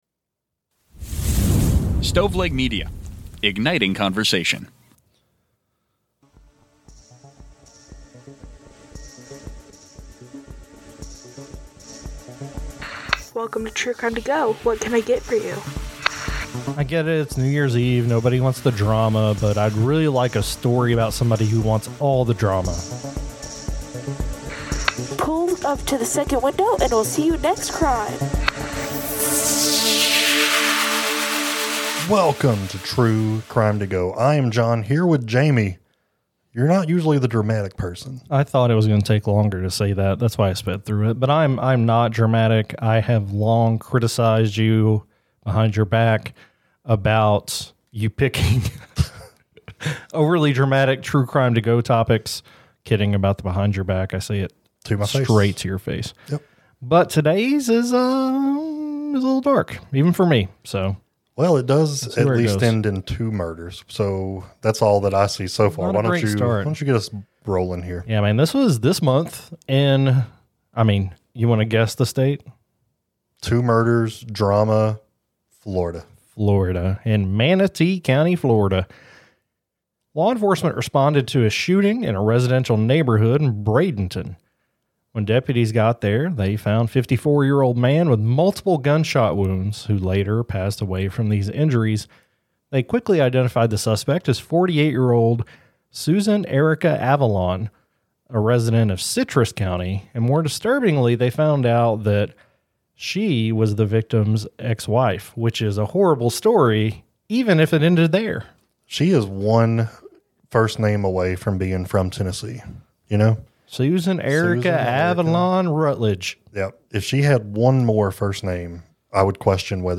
Each episode takes on a different case and the hosts share both details and theories about what really happened.